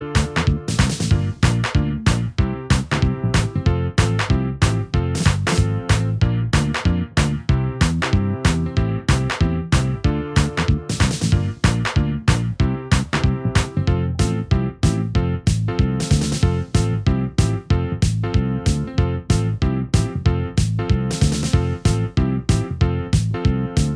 Minus All Guitars Pop (1980s) 2:13 Buy £1.50